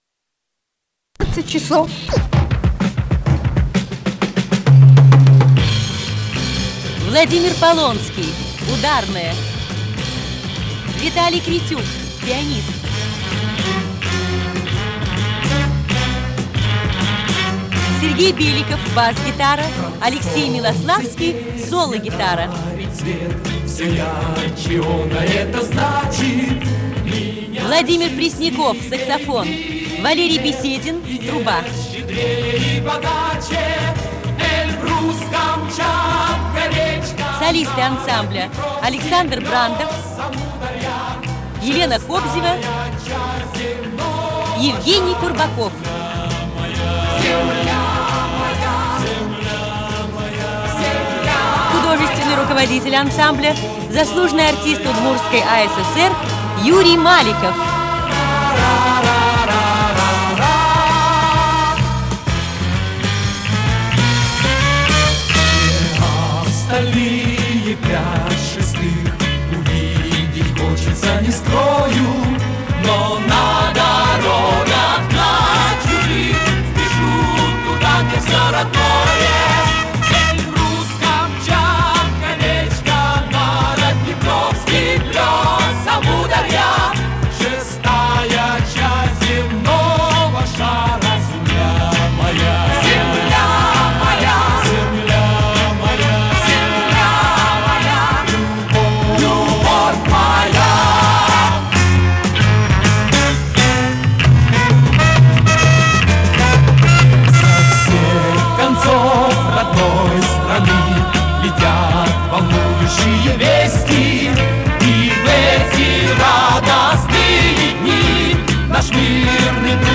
Запись с концерта 76 года